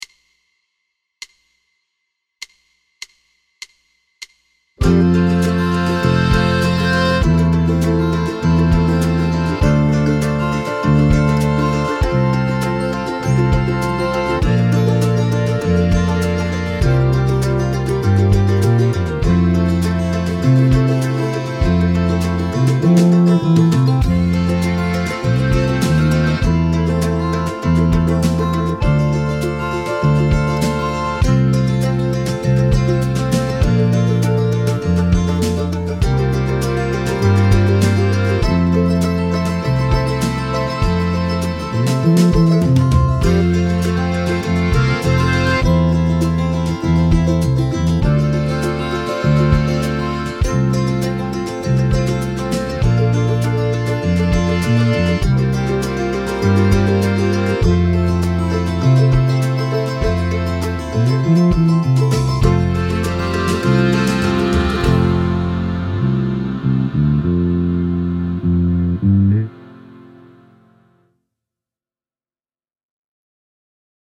Die Melodie ist sehr einfach.
Playback: Bb Tenor